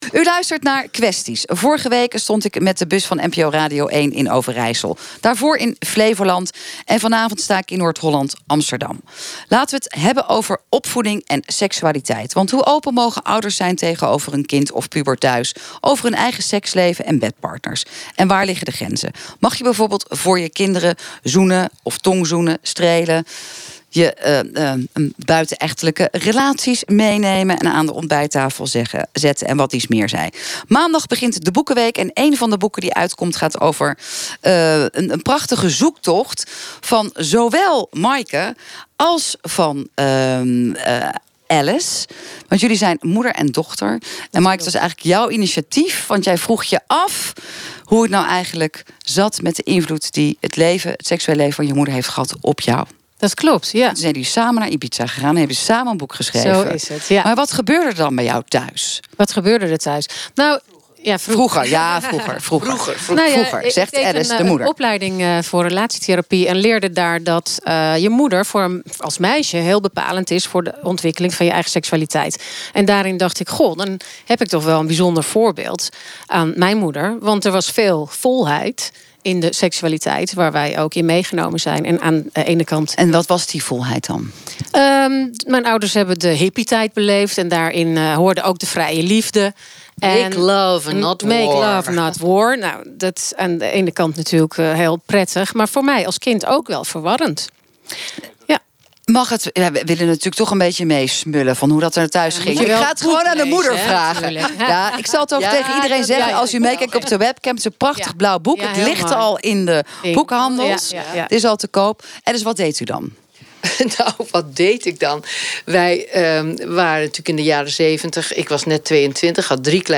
Op zondag 17 maart 2019 namen we deel aan het gesprek bij het NPO Radio 1 programma Kwesties over het onderwerp: hoe open ben je als ouders over seks ten opzichte van je kinderen? Beluister hier dat gedeelte van de uitzending.
Kwesties-Debat-over-ouders-en-hun-seksleven-NPO-Radio-1.m4a